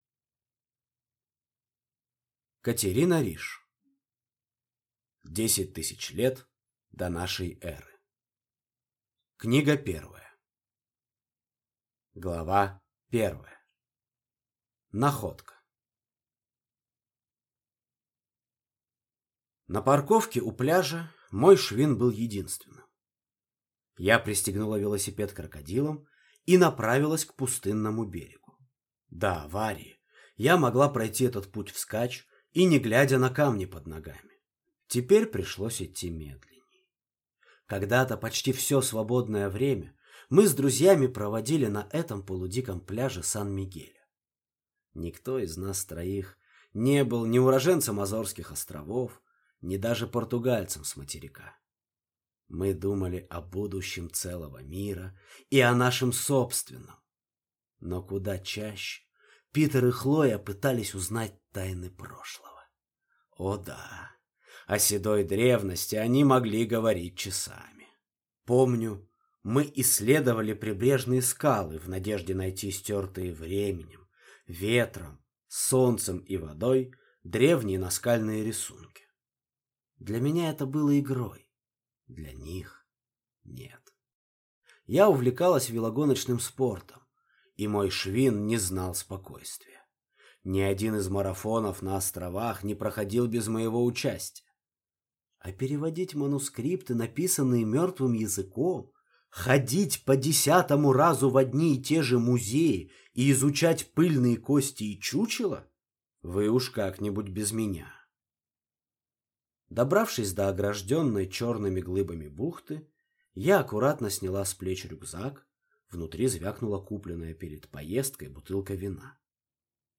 Аудиокнига 10000 лет до нашей эры. Книга 1 | Библиотека аудиокниг